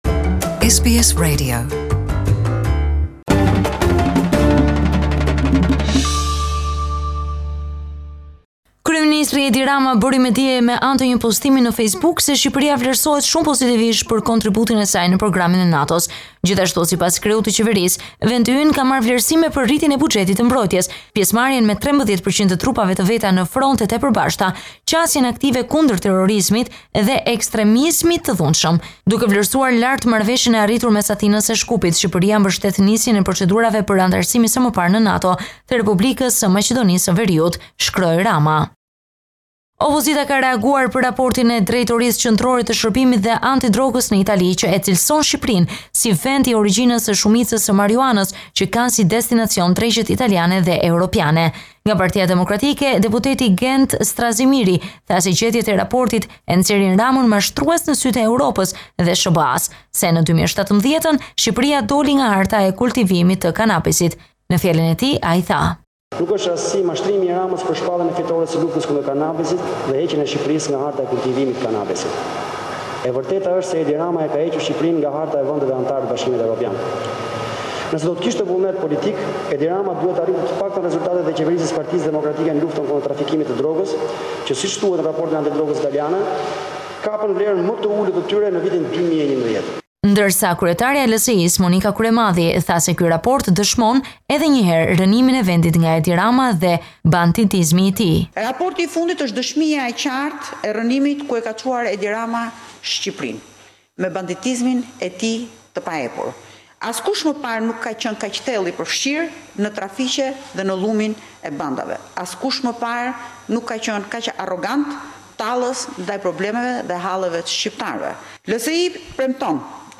Piramida, Tirane (SBS Albanian ) This is a report summarising the latest developments in news and current affairs in Albania